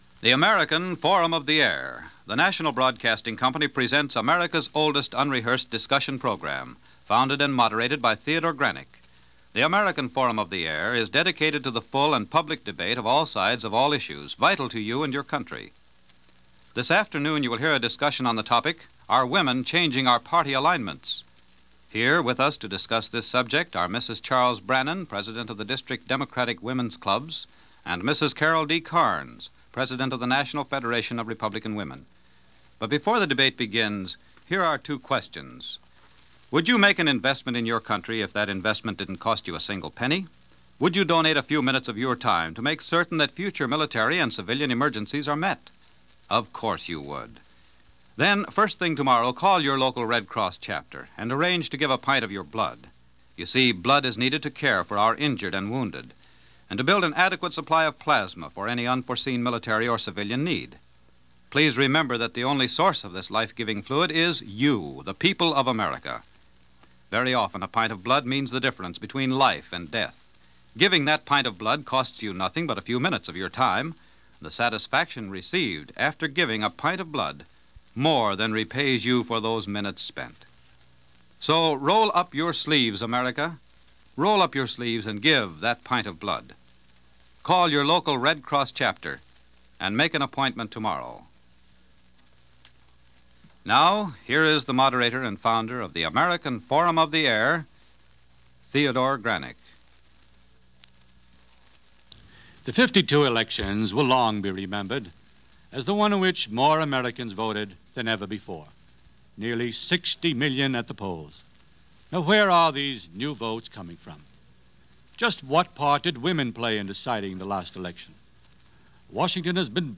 Are Women Changing Our Party Alignments? - 1953 - Women In Politics - Past Daily Reference Room - American Forum Discussion.